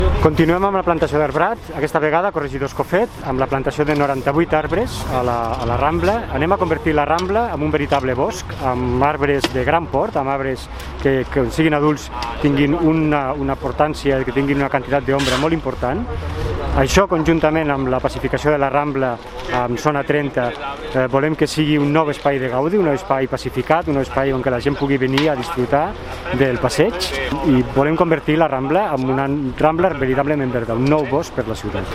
Decarregar imatge original Fitxers relacionats Tall de veu del tinent d'alcalde Sergi Talamonte sobre la plantació d'arbrat